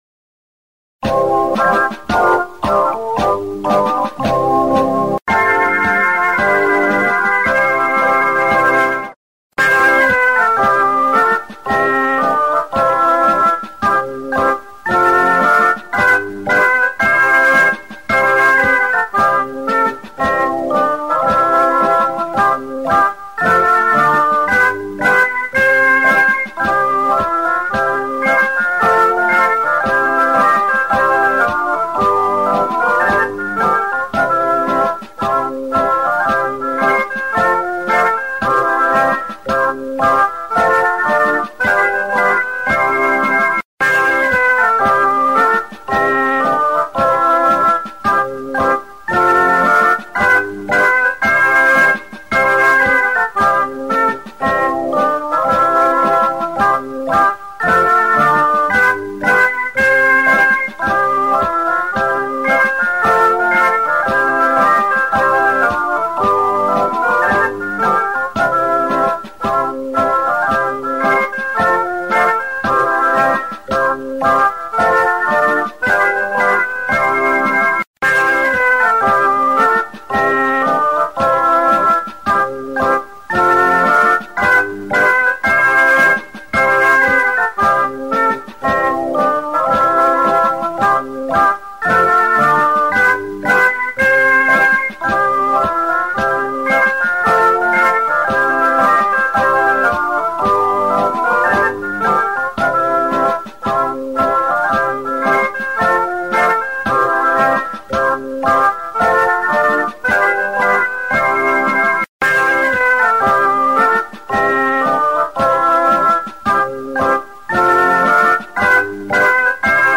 [This accompaniment includes a prelude]
Words: R. George Halls
Music: R. George Halls